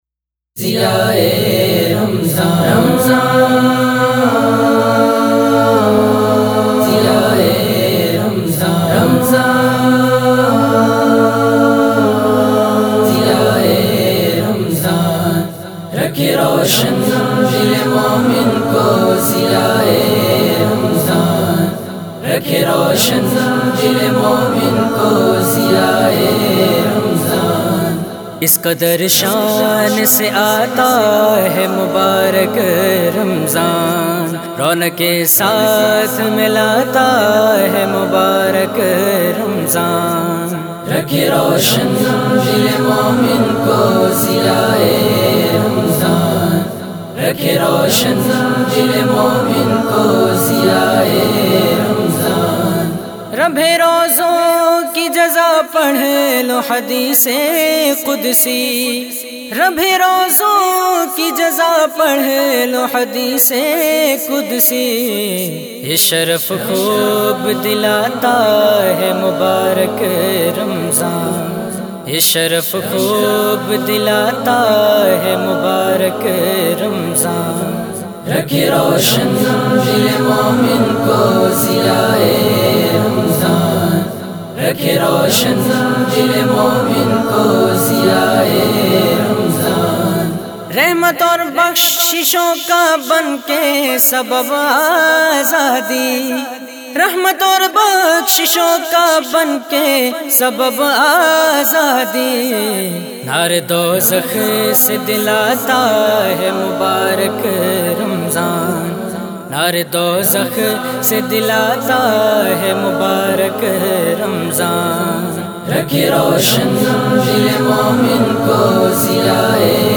کلام